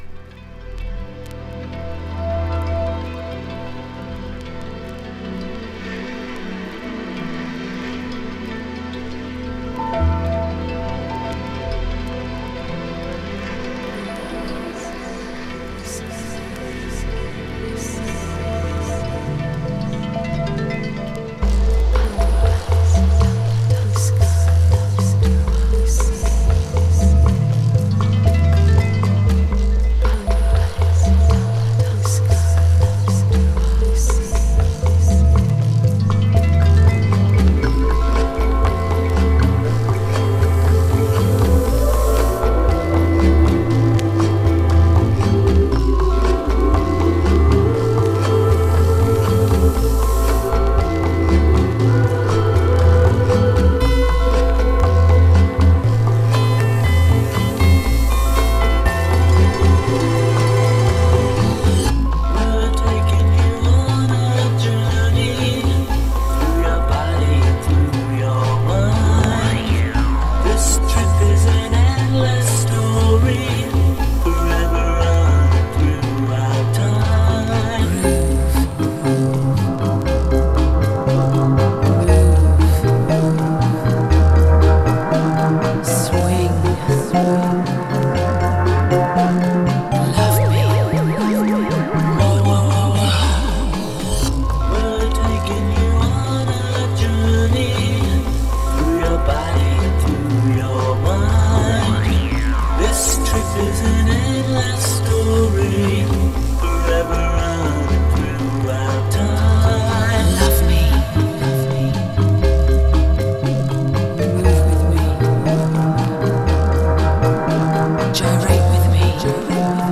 エレクトロニカあり、エレクトロブレイクビーツあり、その他様々なエレクトロミュージック満載です。